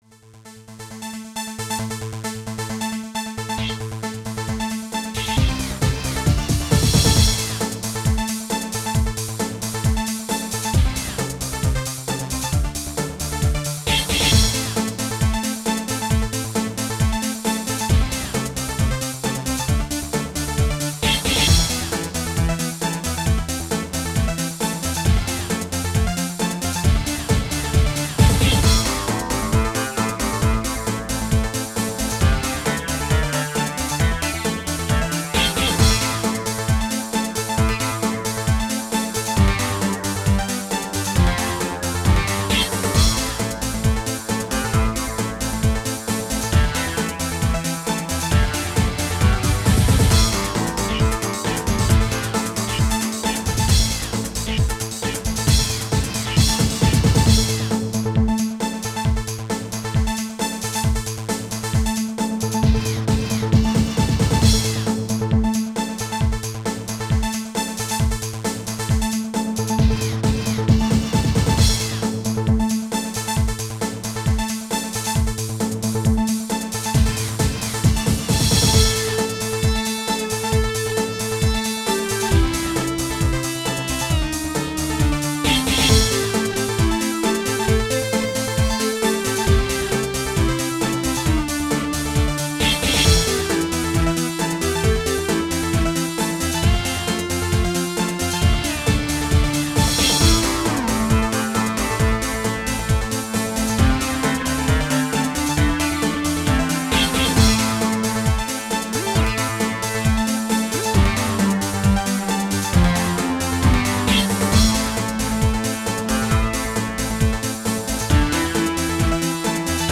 Style: Dance